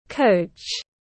Huấn luyện viên tiếng anh gọi là coach, phiên âm tiếng anh đọc là /kəʊtʃ/.
Coach /kəʊtʃ/